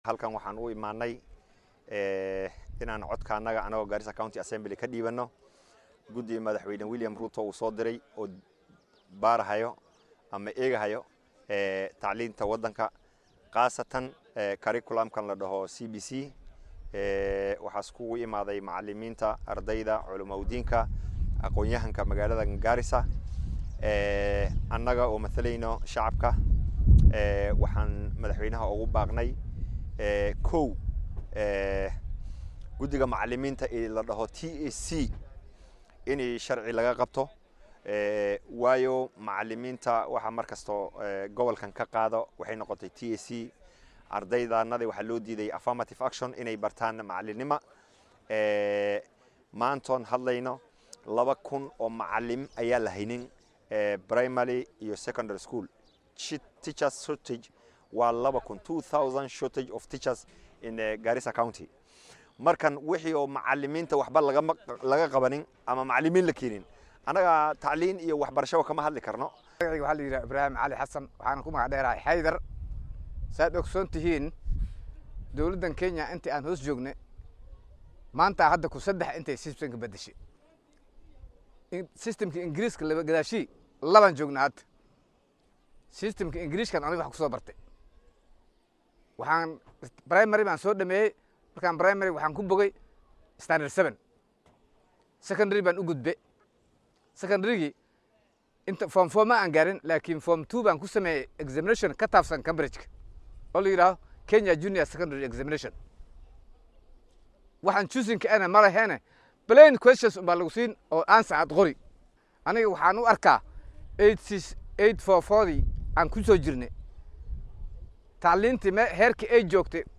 Qaar ka mid ah mas’uuliyin iyo dad shacab ah oo halkaas ka hadlay ayaa intooda badan isku raacay in manhajka CBC-da uusan wax faa’iido ah u lahayn ardayda Garissa. Mas’uuliyiinta iyo dadkii kale ka soo qayb galay kulanka oo la hadlay warbaahinta star ayaa ka warbixiyay wax ay u diidayaan CBC